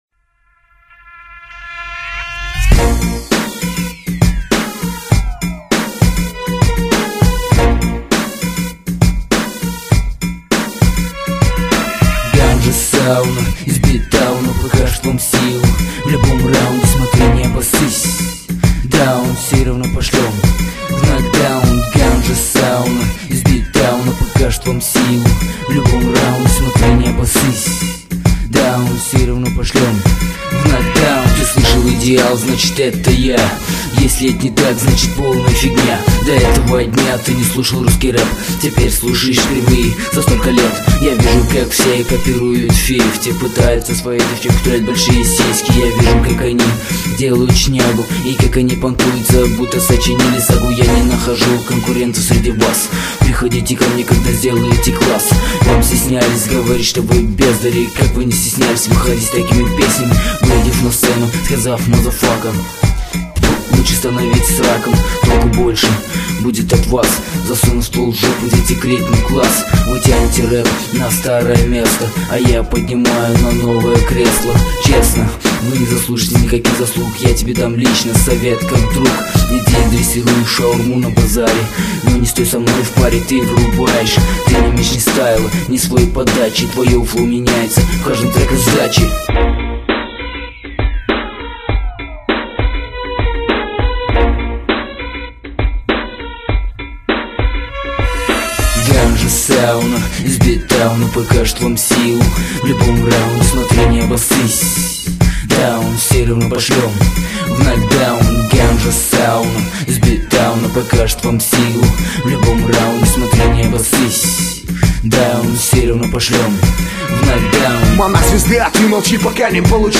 Жанр:Rap